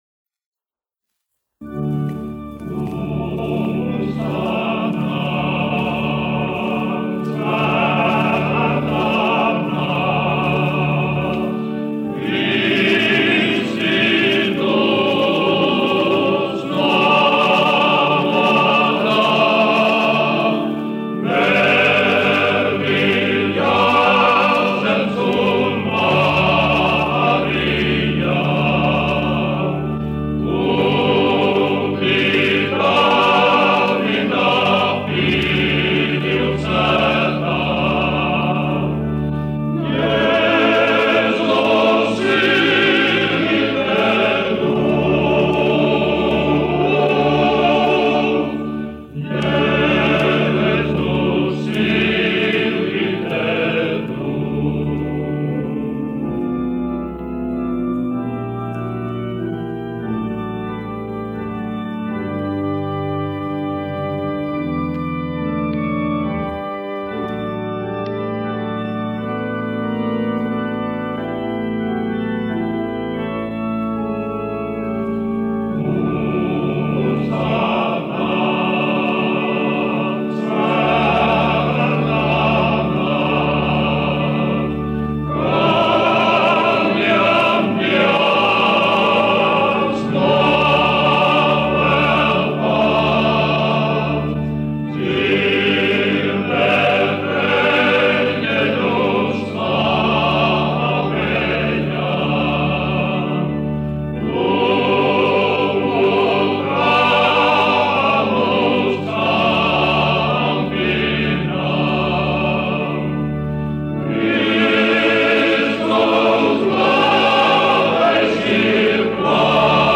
Ņujorkas Daugavas Vanagu vīru koris, izpildītājs
1 skpl. : analogs, 78 apgr/min, mono ; 25 cm
Ziemassvētku mūzika
Kori (vīru)